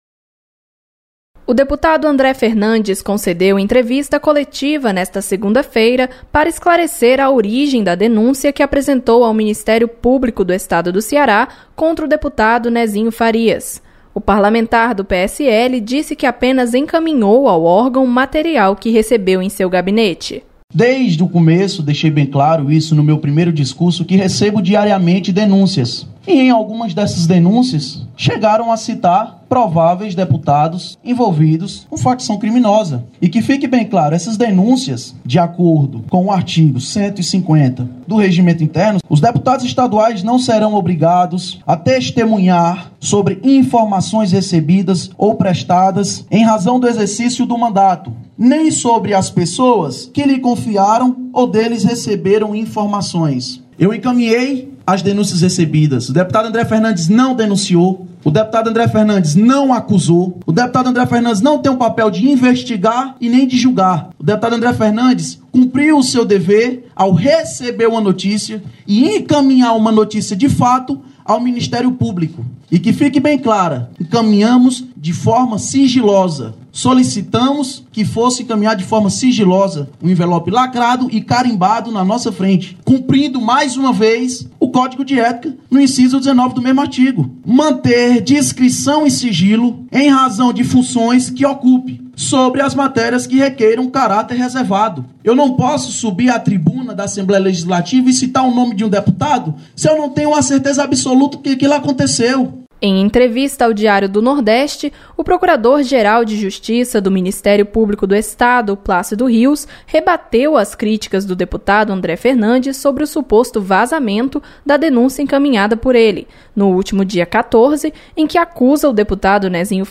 Coletiva